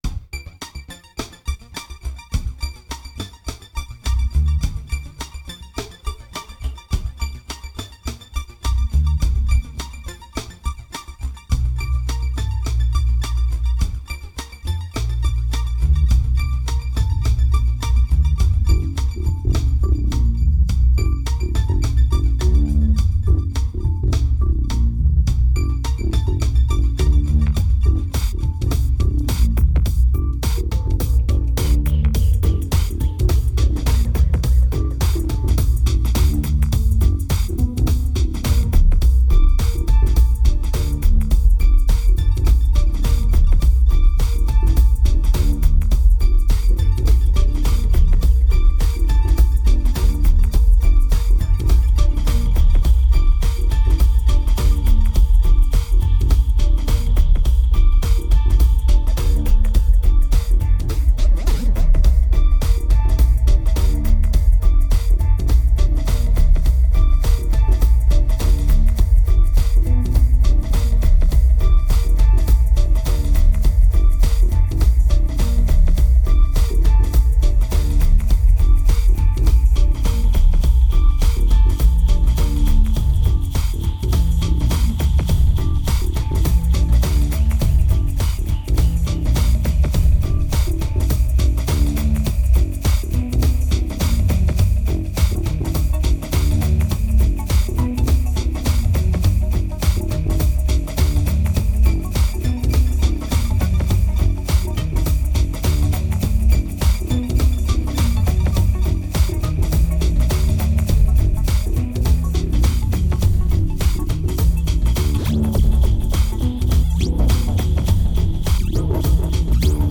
1887📈 - -3%🤔 - 105BPM🔊 - 2010-04-09📅 - -298🌟